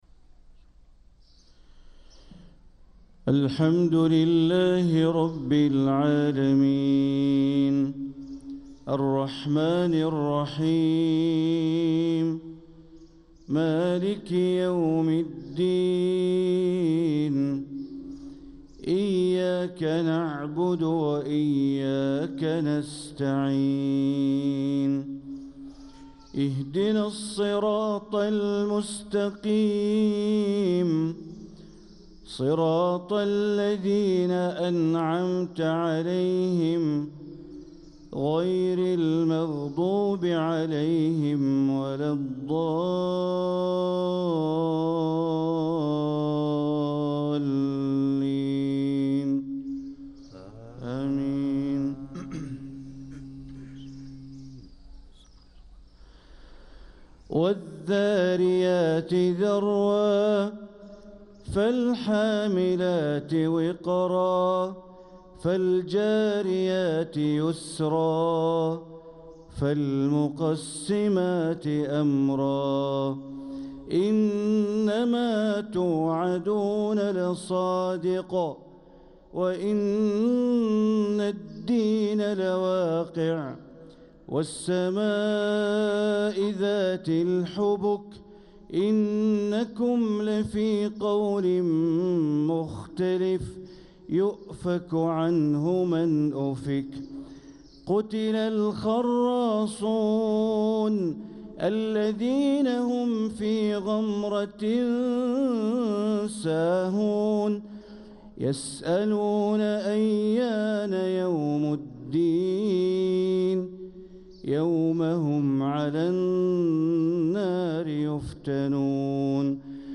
صلاة الفجر للقارئ بندر بليلة 15 رجب 1446 هـ
تِلَاوَات الْحَرَمَيْن .